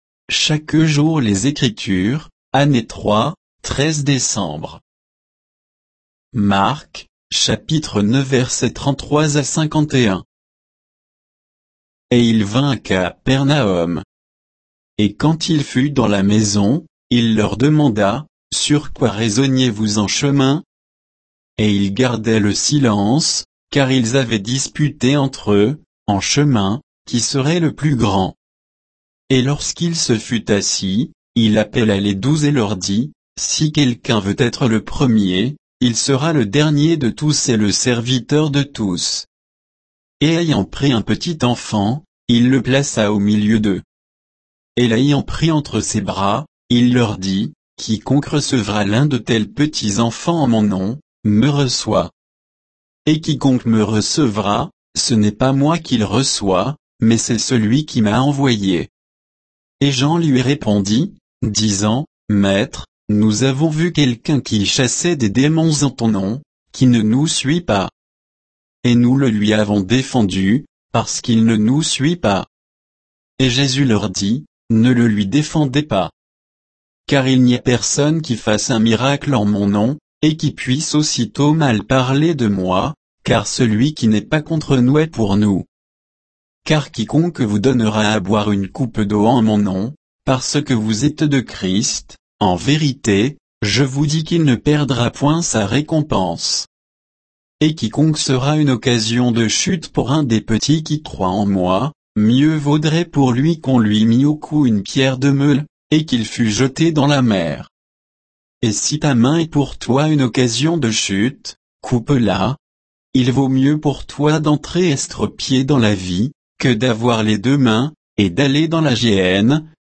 Méditation quoditienne de Chaque jour les Écritures sur Marc 9